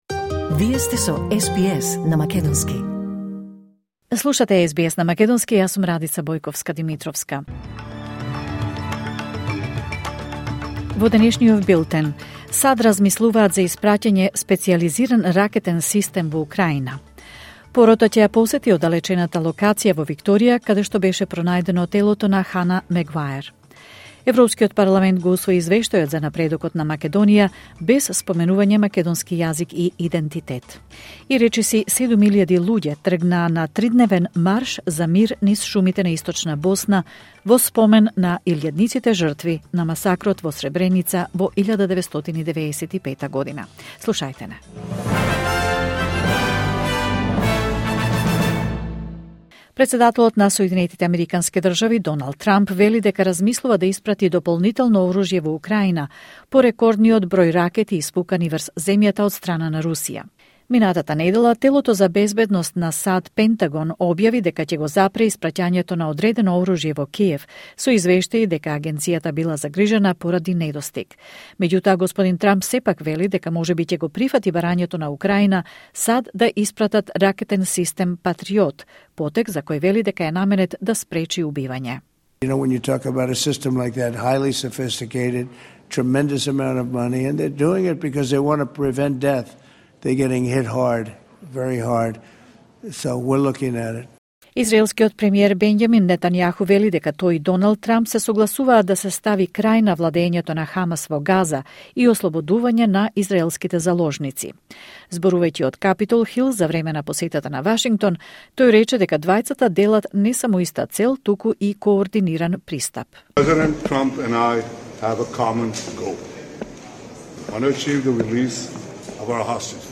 Вести на СБС на македонски 10 јули 2025